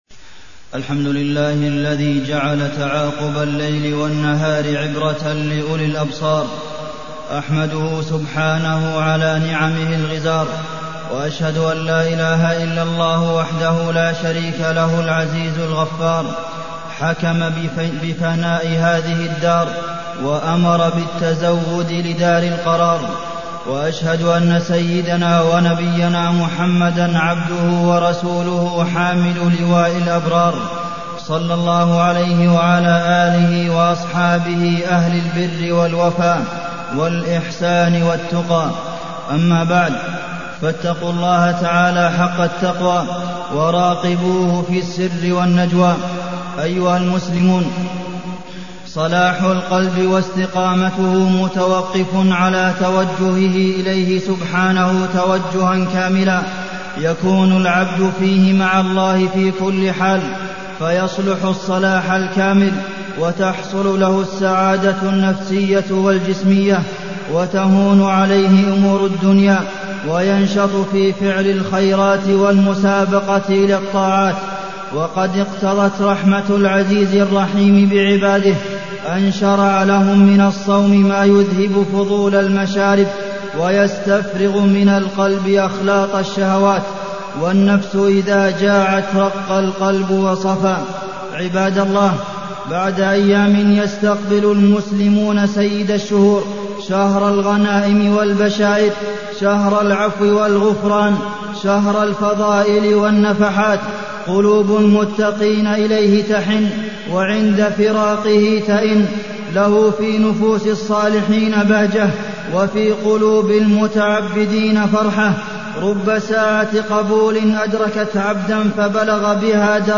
تاريخ النشر ٢٥ شعبان ١٤٢٠ هـ المكان: المسجد النبوي الشيخ: فضيلة الشيخ د. عبدالمحسن بن محمد القاسم فضيلة الشيخ د. عبدالمحسن بن محمد القاسم شهر رمضان